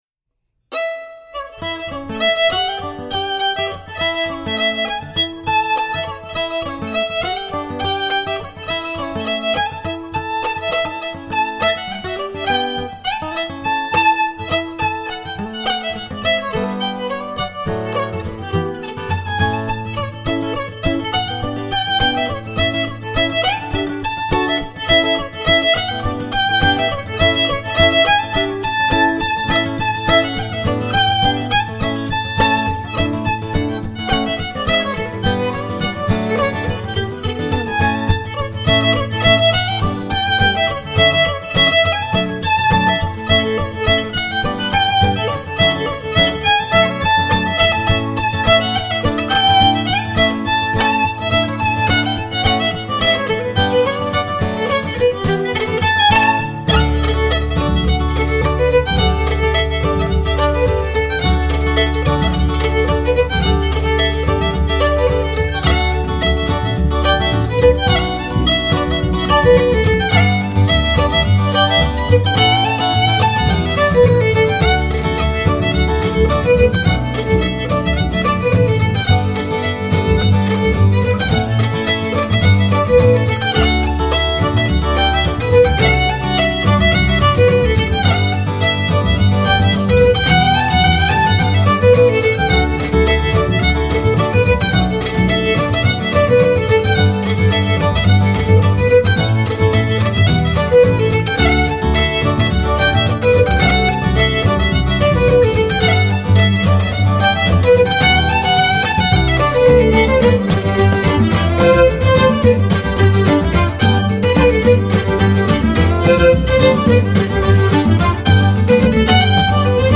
Scottish and Québécois fiddling.
à la guitare
au piano
aux percussions